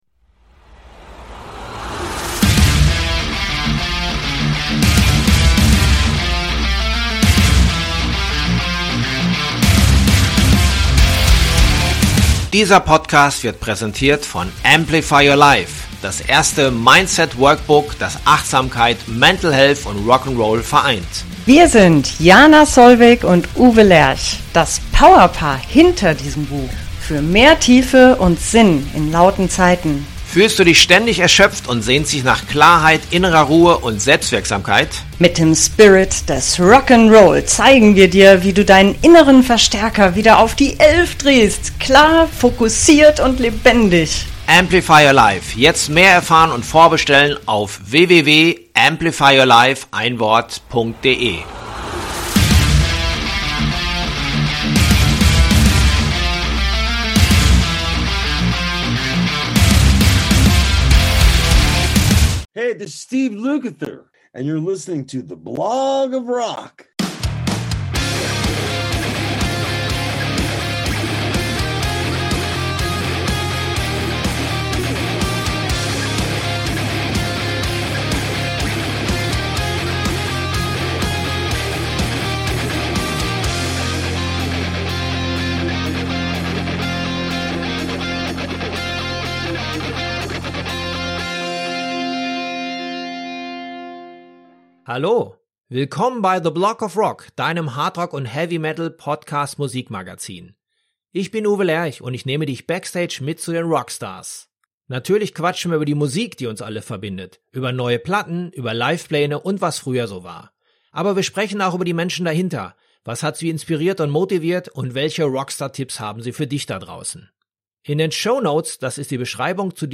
Ich habe mich im Hinblick auf mein Interview ausführlich mit seiner Biographie beschäftigt und da ist mir echt die Kinnlade runtergefallen.